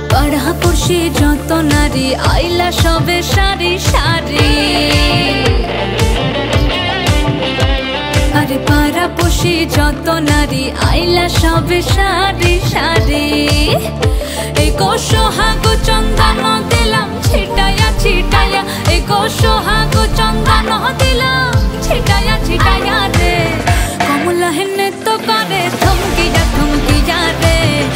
Song Ringtones